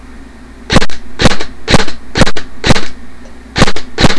С глушителем
silencer.wav